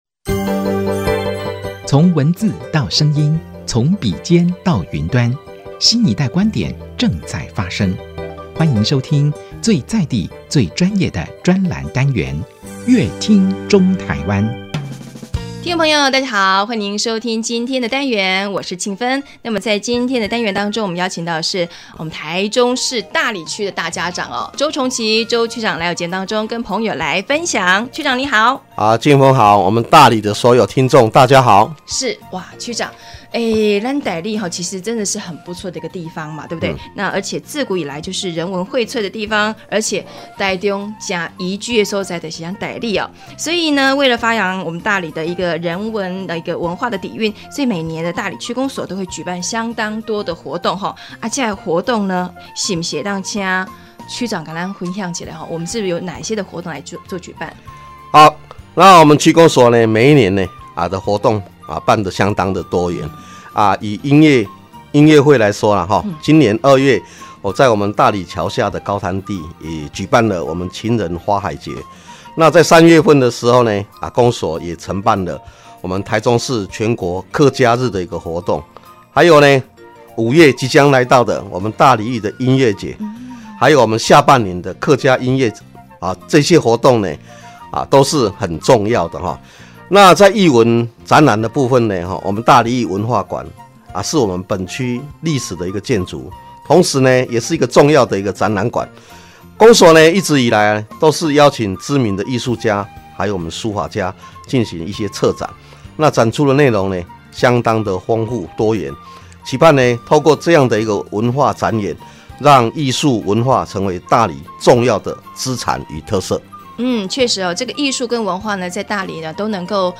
本集來賓：台中市大里區周崇琦區長 本集主題：融合地景與文化底蘊 一起「樂」來「越」好 本集內容： 一府二鹿三艋